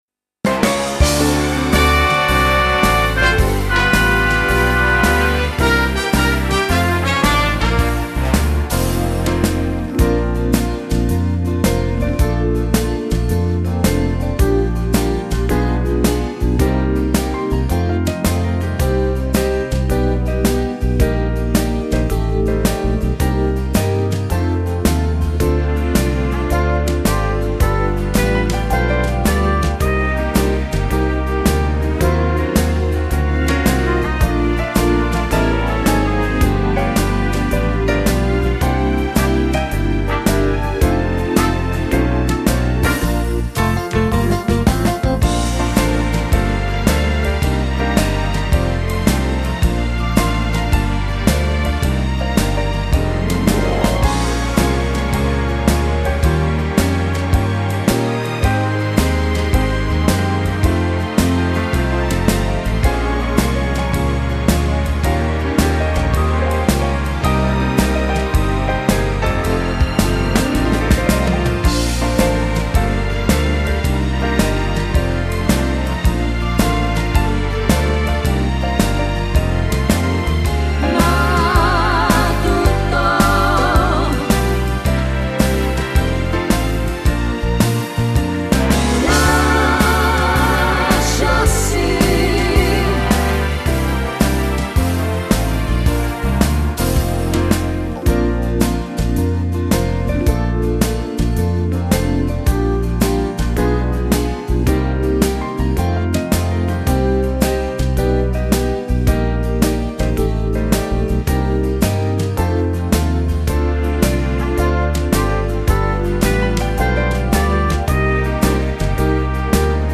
Genere: Swing moderato
Scarica la Base Mp3 (3,51 MB)